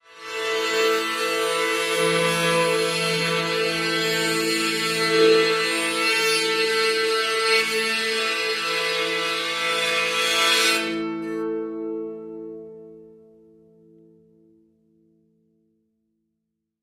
Mandolin | Sneak On The Lot